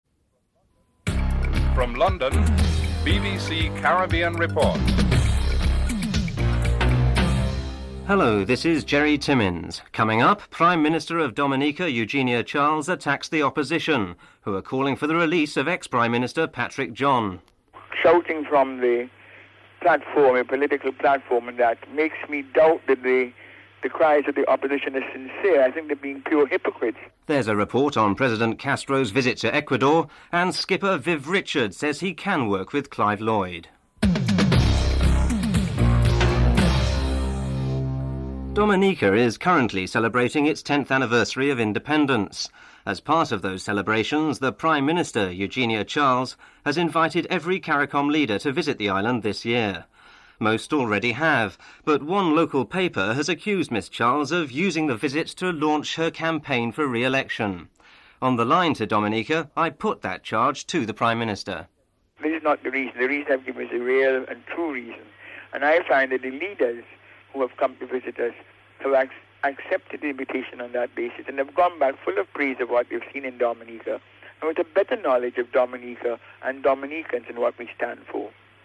1. Headlines: Prime Minister of Dominica Eugenia Charles attacks the opposition who call for the release of former Prime Minister Patrick John.